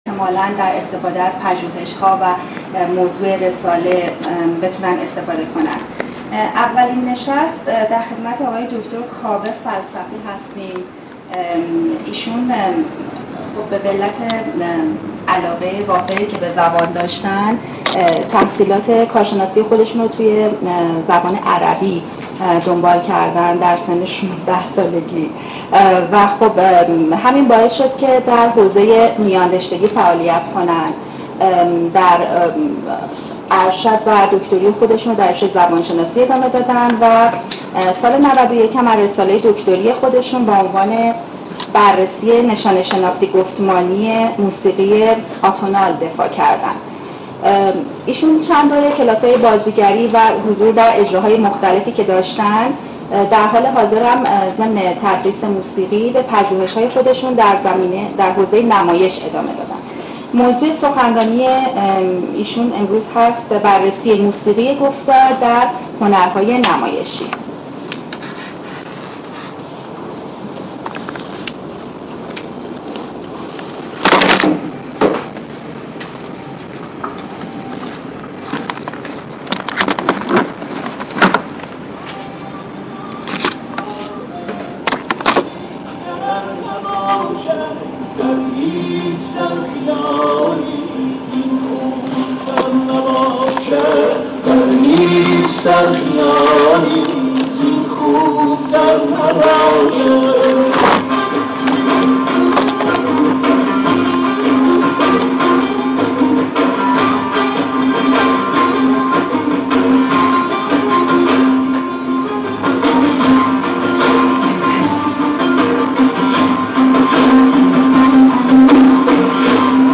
دومین نشست از سلسله نشست های علمی دانش آموختگان پژوهشکده زبان شناسی